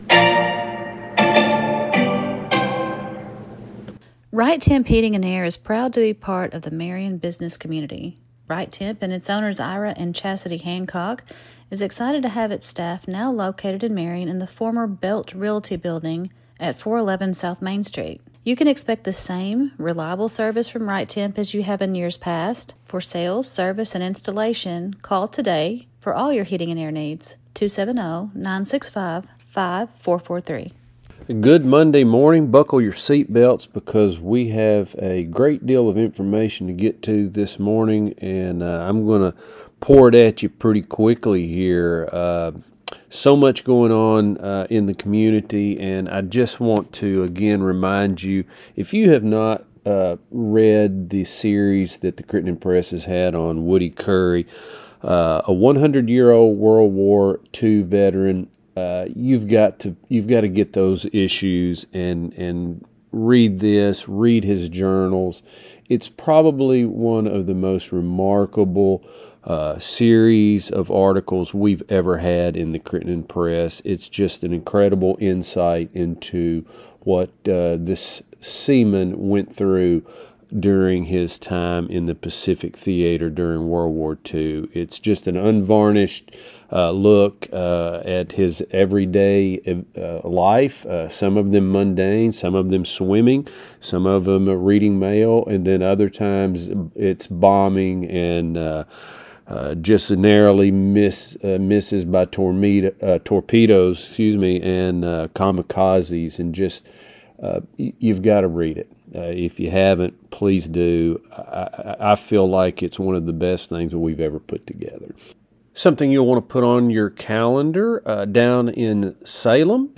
THURSDAY'S LOCAL NEWScast